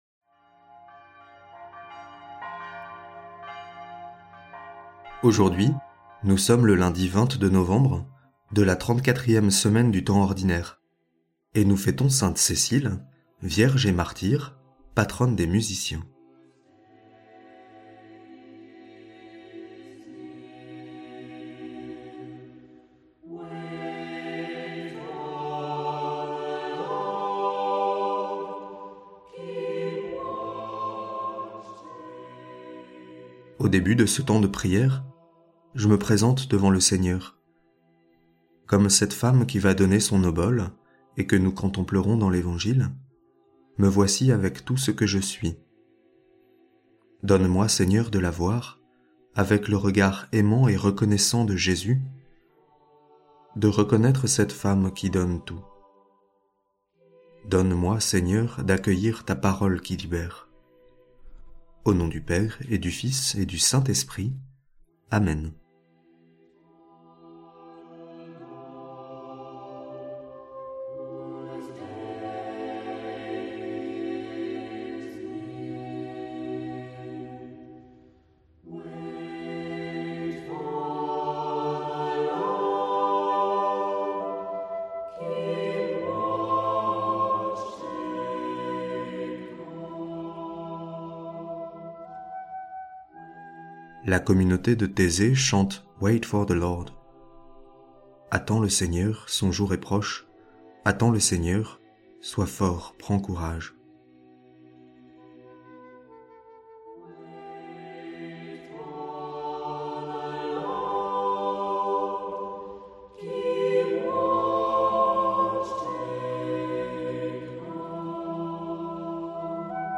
Musiques